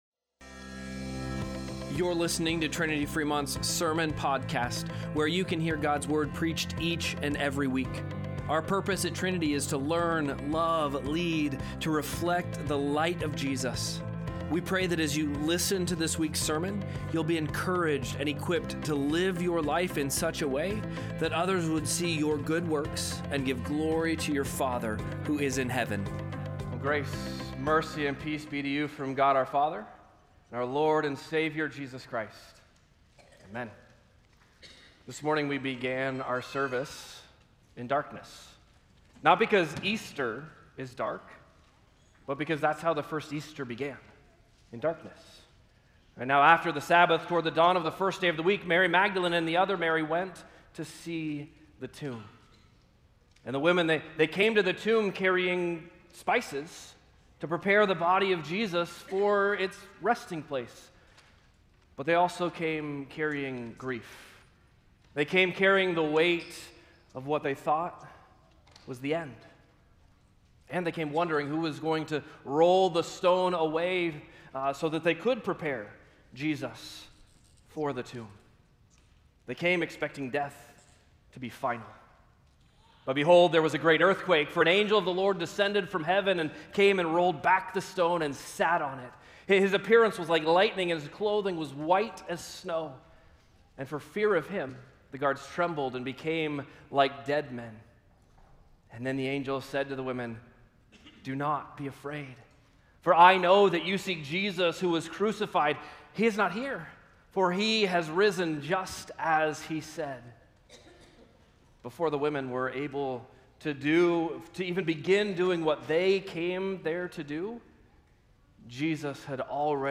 Sermon-Podcast-Easter-26.mp3